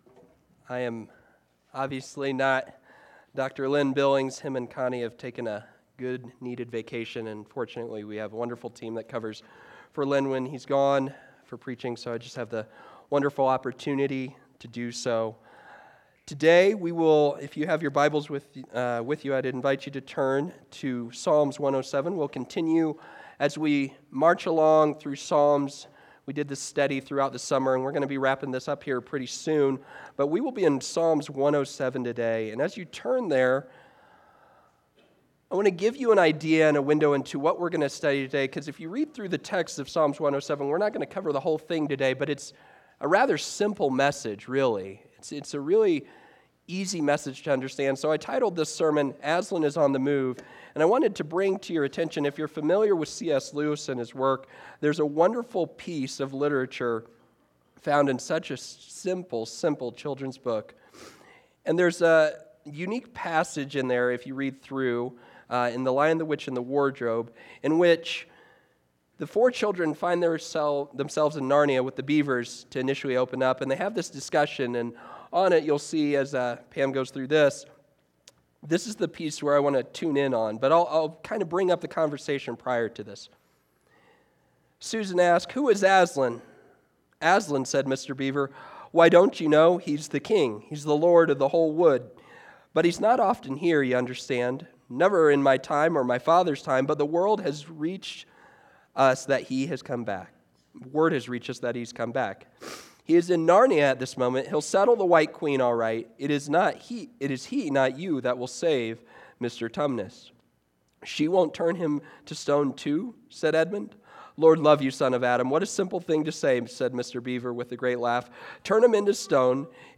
Sermons | First Baptist Church of Golden
Guest Speaker